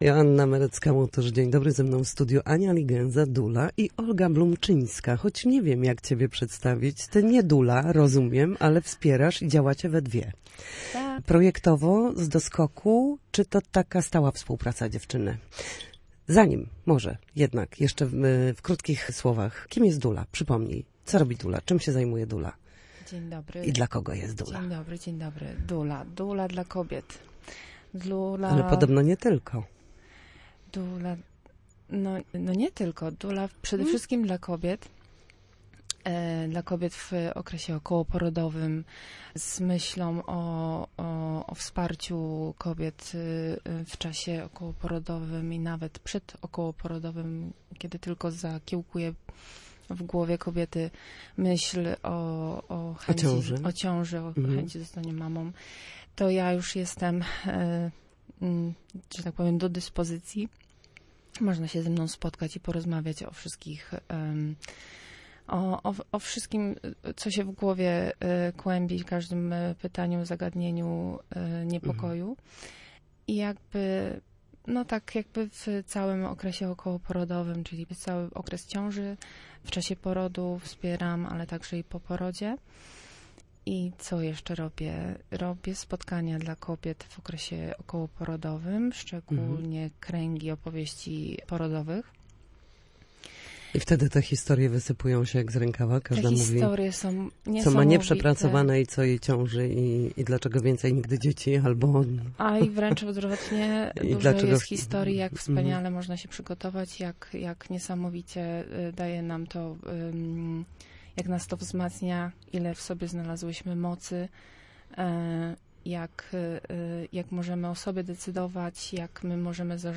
Doula i mozaika kobiecych opowieści w Studio Słupsk Radia Gdańsk
W każdą środę, w popołudniowym Studiu Słupsk Radia Gdańsk dyskutujemy o tym, jak wrócić do formy po chorobach i urazach.